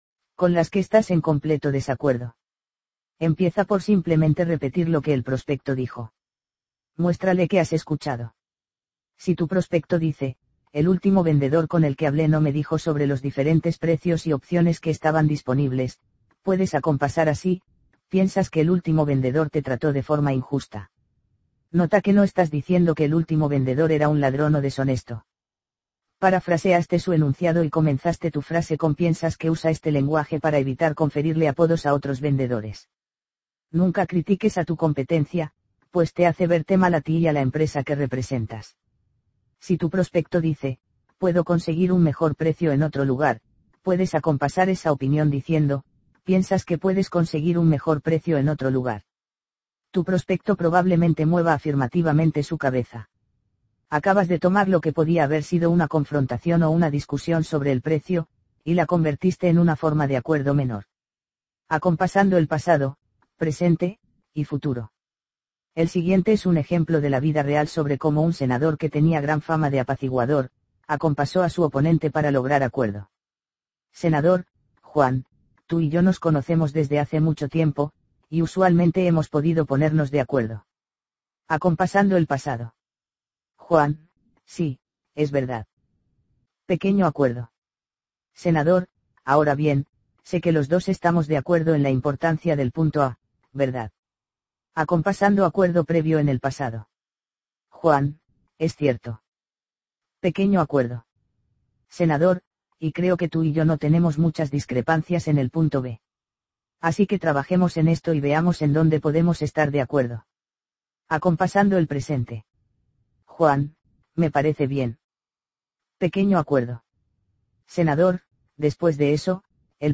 Audiolibro-El-vendedor-elegantemente-irresistible-Parte-4.mp3